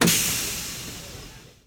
Doors.wav